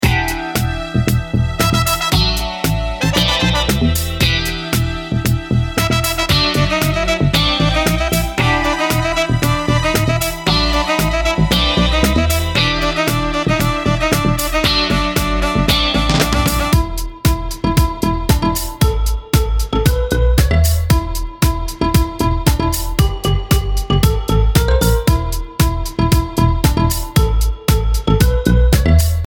a 30-second sample for commercial use